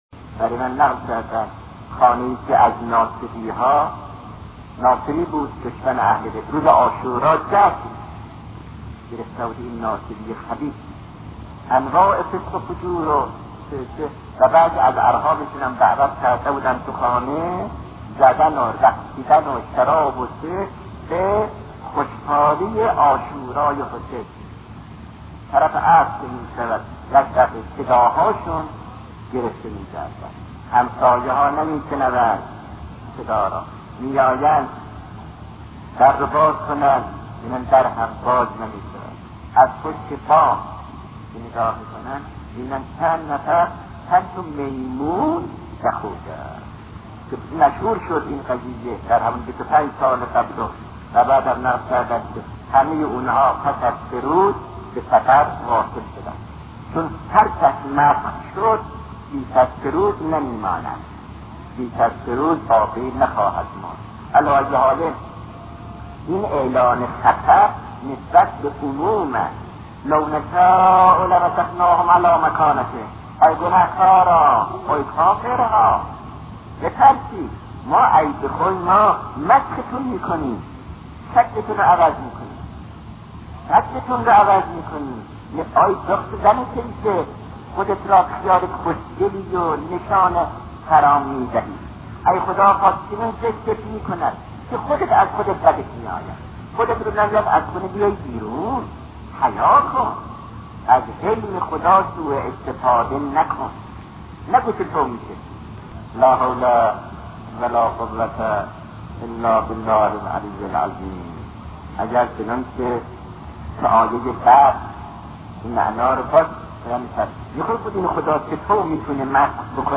وبلاگ تخصصی سخنرانی - آیت الله دستغیب ( گناه).mp3